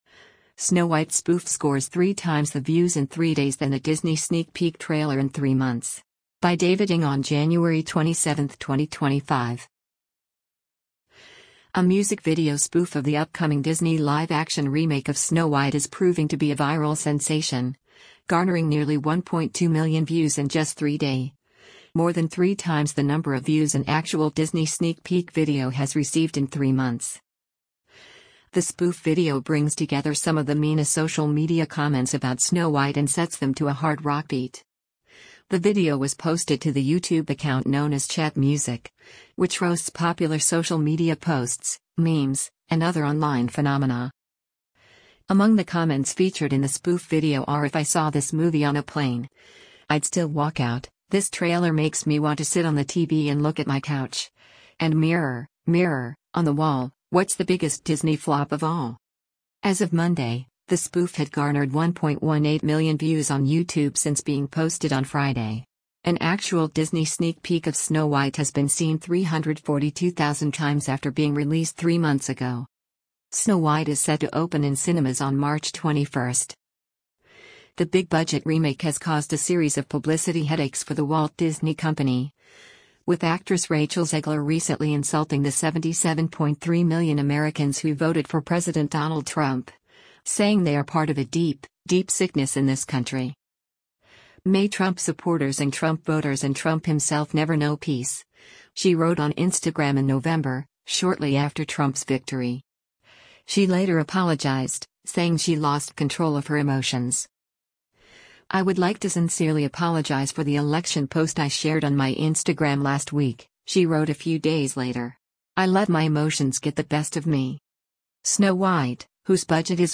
A music video spoof of the upcoming Disney live-action remake of Snow White is proving to be a viral sensation, garnering nearly 1.2 million views in just three day — more than three times the number of views an actual Disney sneak peek video has received in three months.
The spoof video brings together some of the meanest social media comments about Snow White and sets them to a hard rock beat.